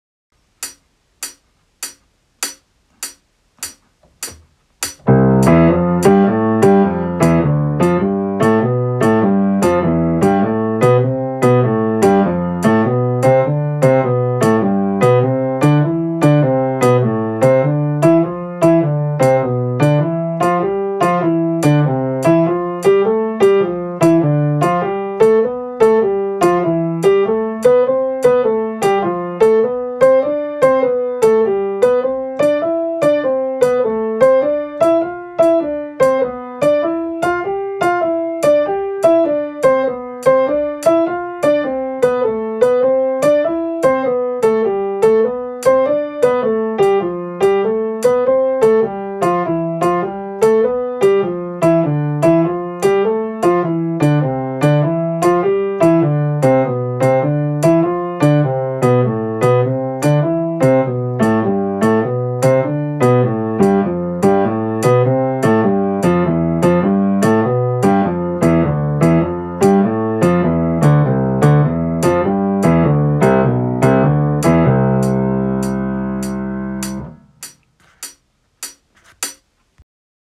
今度は、先ほど説明した＜ドゥーダ＞の＜ダ＞の部分、つまり裏拍でメトロノームが鳴るようにします。
メトロノームは♩ =100　に戻します。（ハイハット音）